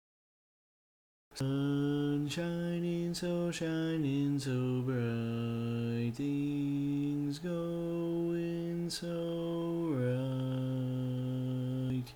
Key written in: F Major
Each recording below is single part only.